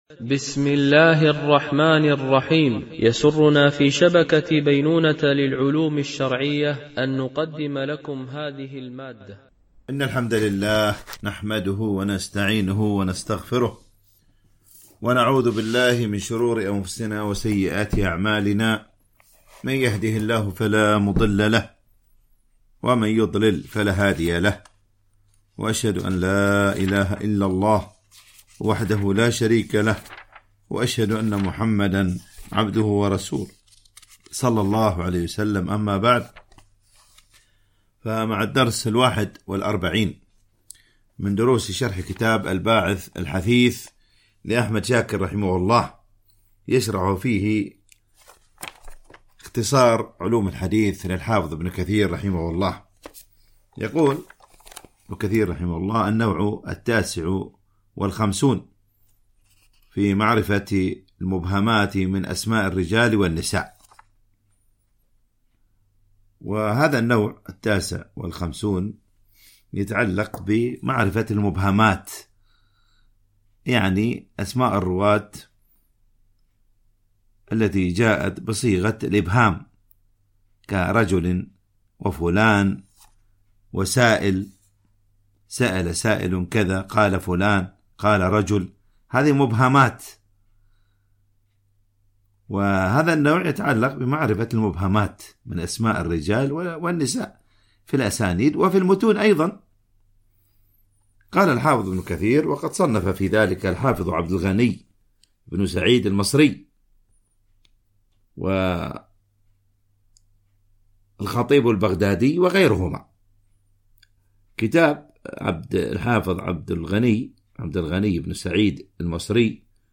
شرح كتاب الباعث الحثيث شرح اختصار علوم الحديث - الدرس 41 ( معرفة المبهمات ووفيات الرواة وأعمارهم )
MP3 Mono 44kHz 64Kbps (VBR)